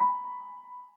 piano20.ogg